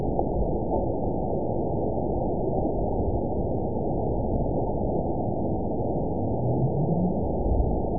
event 920826 date 04/10/24 time 23:14:39 GMT (1 year, 1 month ago) score 9.62 location TSS-AB02 detected by nrw target species NRW annotations +NRW Spectrogram: Frequency (kHz) vs. Time (s) audio not available .wav